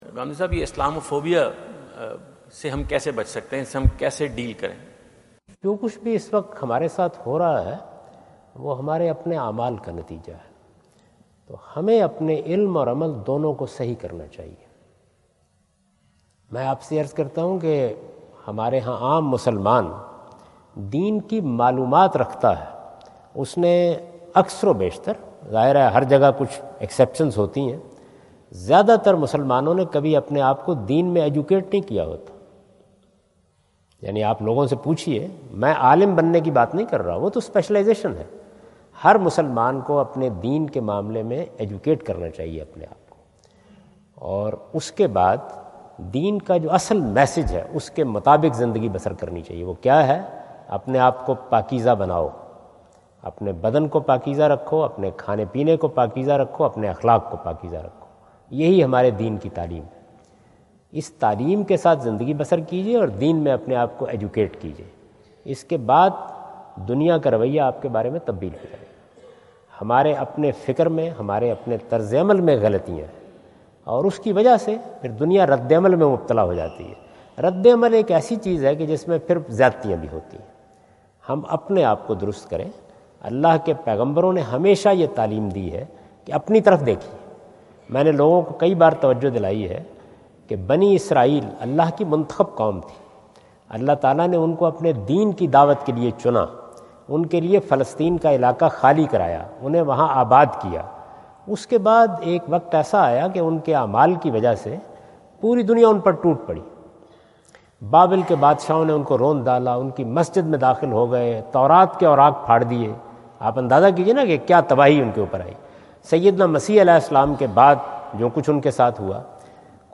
Javed Ahmad Ghamidi answer the question about "What is Islam phobia?" During his US visit at Wentz Concert Hall, Chicago on September 23,2017.
جاوید احمد غامدی اپنے دورہ امریکہ2017 کے دوران شکاگو میں "اسلام فوبیا کیا ہے؟" سے متعلق ایک سوال کا جواب دے رہے ہیں۔